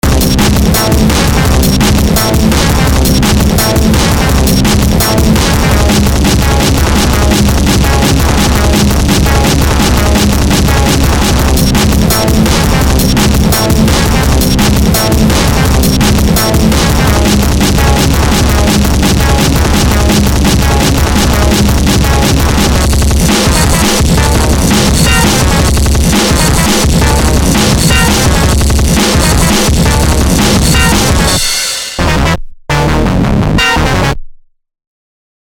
breakcore, shitcore, noisecore, glitch,